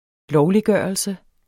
Udtale [ -ˌgɶˀʌlsə ]